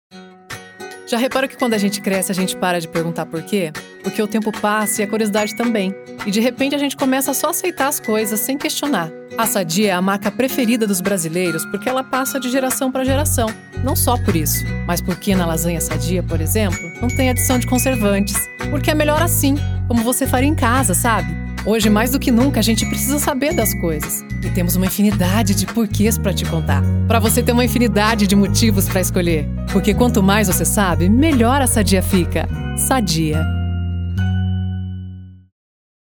Loc. presente: